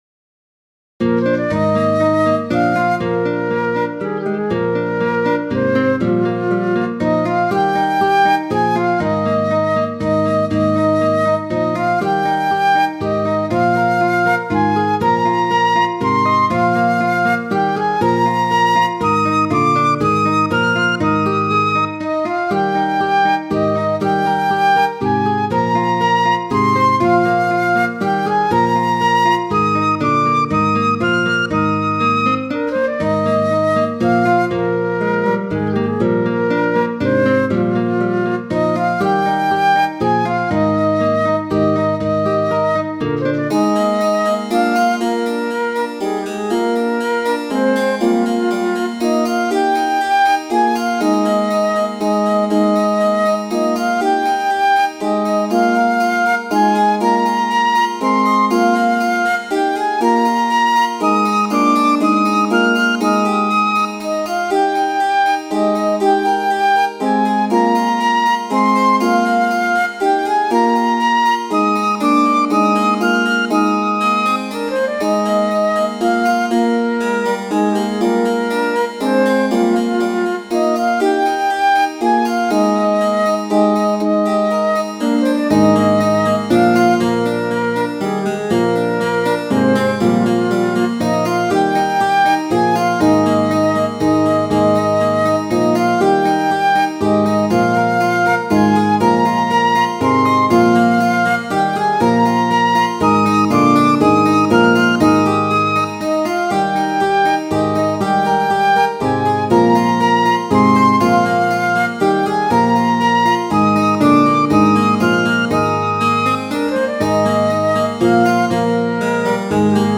This tune is an Irish tune
wilowtre.mid.ogg